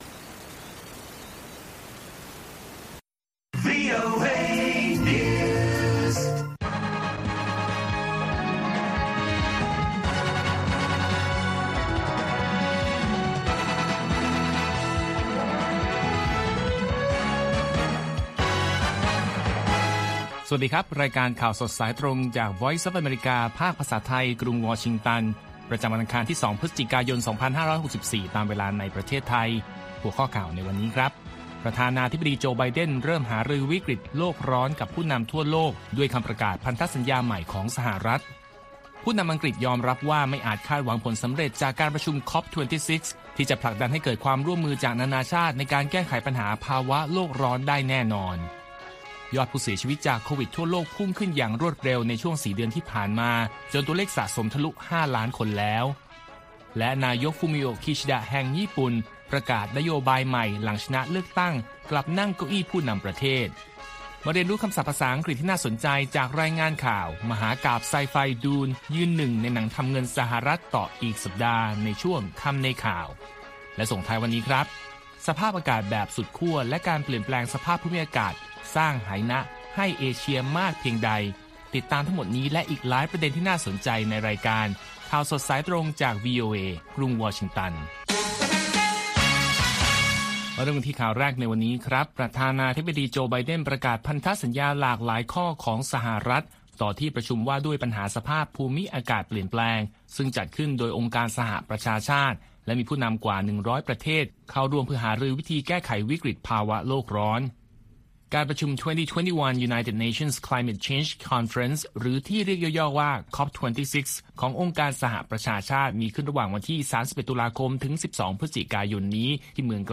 ข่าวสดสายตรงจากวีโอเอ ภาคภาษาไทย ประจำวันอังคารที่ 2 พฤศจิกายน 2564 ตามเวลาประเทศไทย